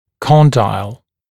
[ˈkɔndaɪl] [-dɪl][ˈкондайл] [-дил]мыщелок